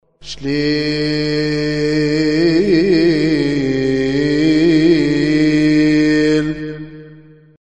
مكتبة الألحان
Shleel-Priest.mp3